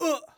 xys被击倒1.wav 0:00.00 0:00.38 xys被击倒1.wav WAV · 33 KB · 單聲道 (1ch) 下载文件 本站所有音效均采用 CC0 授权 ，可免费用于商业与个人项目，无需署名。
人声采集素材